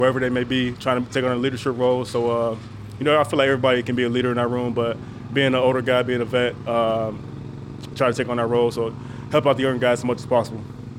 Chiefs safety Mike Edwards.